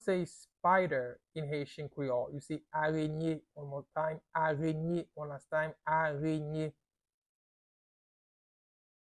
Listen to and watch “Arenye” audio pronunciation in Haitian Creole by a native Haitian  in the video below:
24.How-to-say-Spider-in-Haitian-Creole-Arenye-pronunciation-by-a-Haitian-teacher.mp3